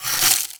ice_spell_freeze_small_02.wav